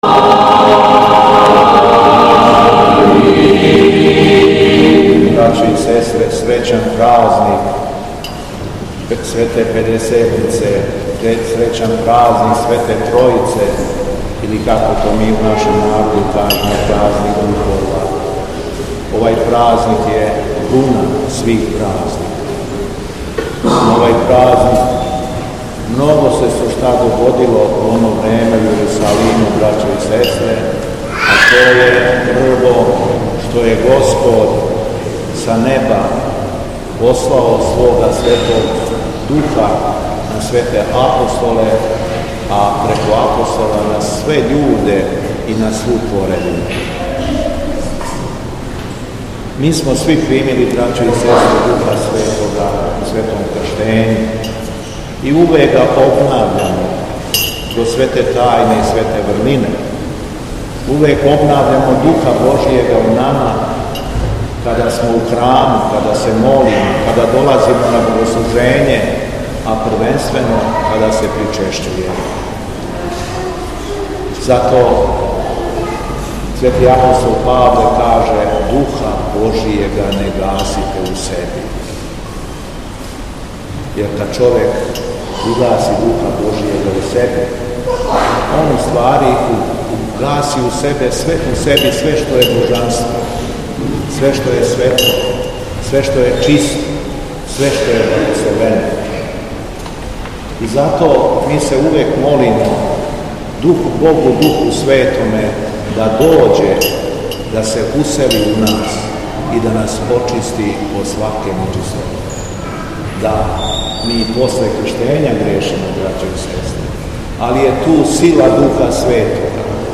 Беседа Његовог Високопреосвештенства Архиепископа крагујевачког и Митрополита шумадијског г. Јована